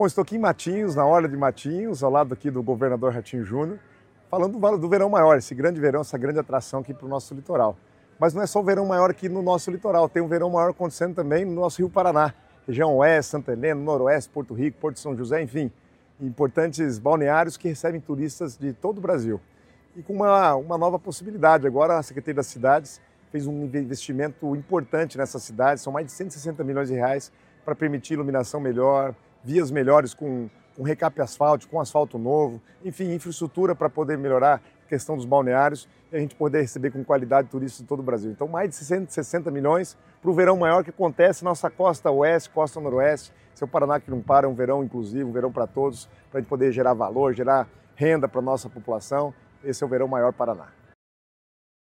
Sonora do secretário das Cidades, Guto Silva, sobre as melhorias em infraestrutura na Costa Noroeste do Estado | Governo do Estado do Paraná